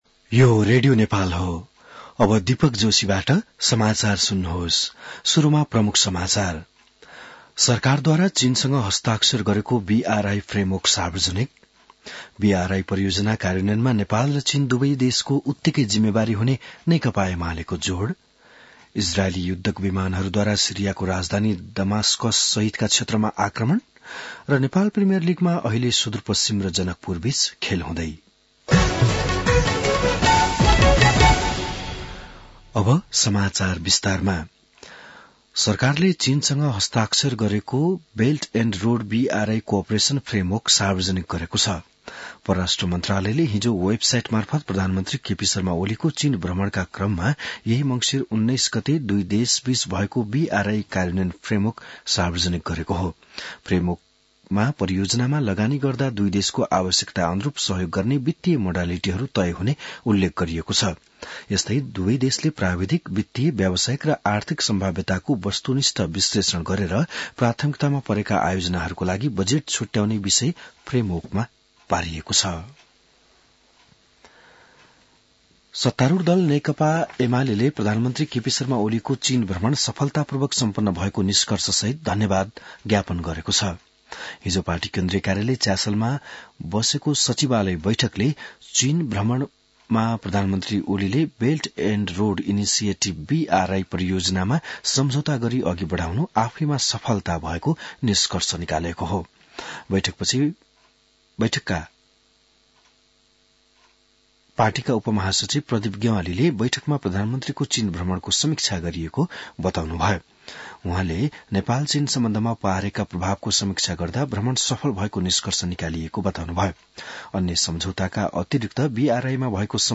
बिहान ९ बजेको नेपाली समाचार : २७ मंसिर , २०८१